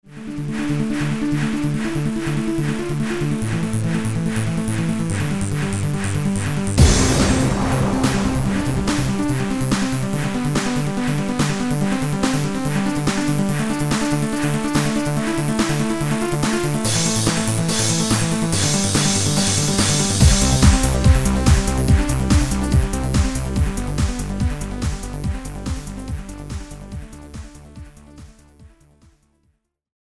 Žánr: Dance
BPM: 143
Key: C